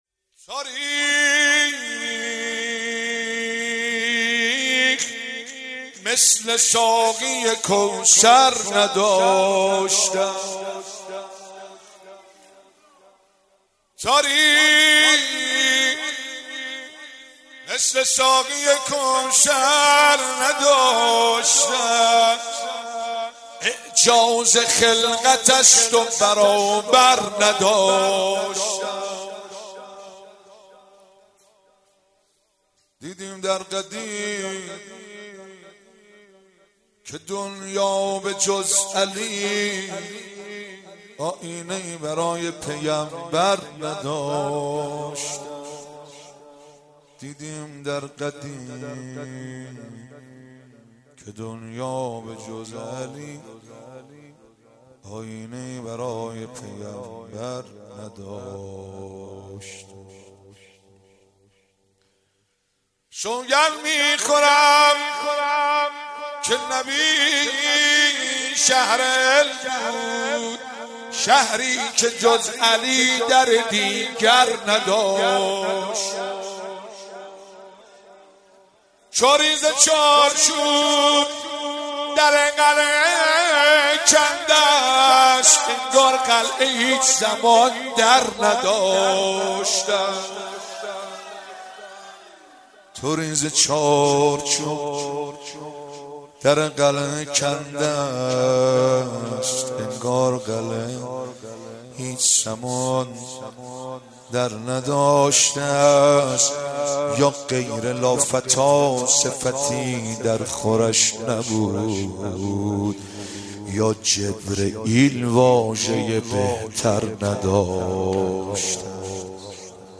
15 فروردین 97 - هیئت فاطمیون قم - شعرخوانی